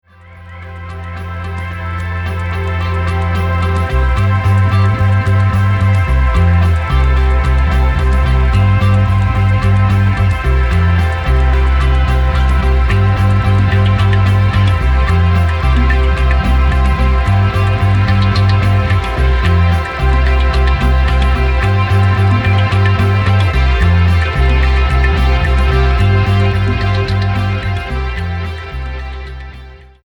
The results cohere into a sonic juggernaut.